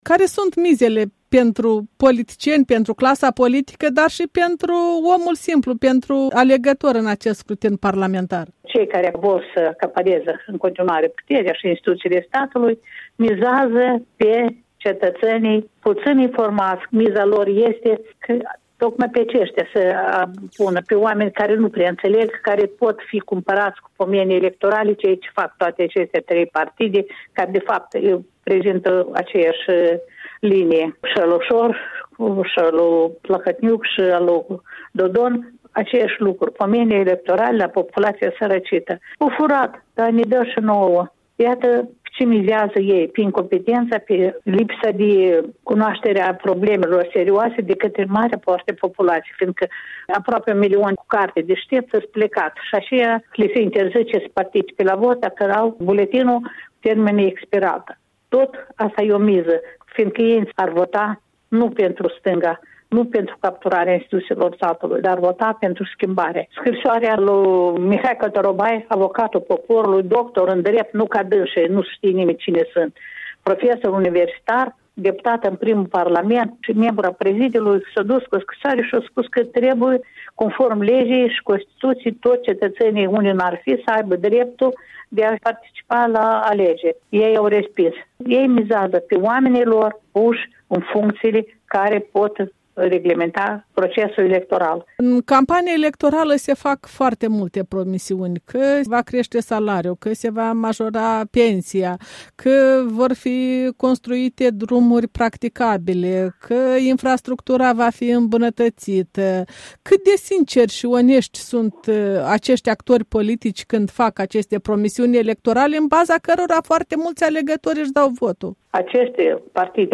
Interviu cu Nadejda Brânzan